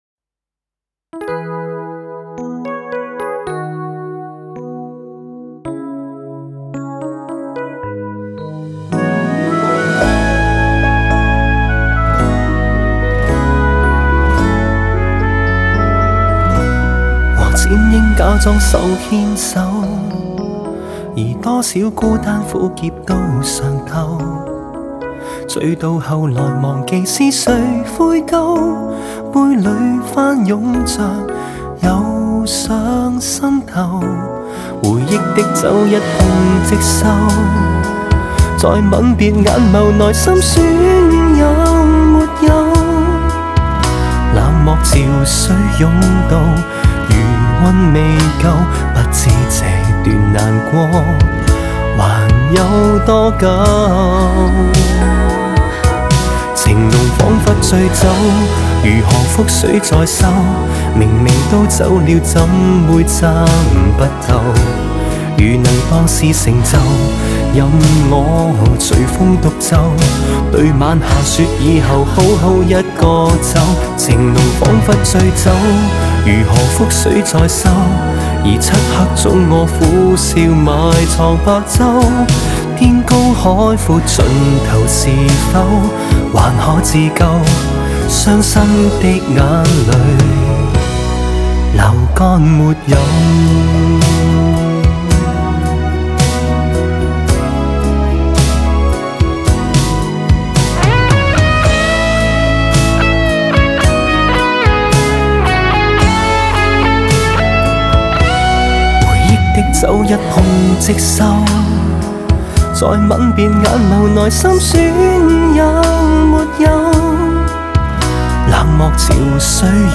Ps：在线试听为压缩音质节选，体验无损音质请下载完整版
和声Backing Vocal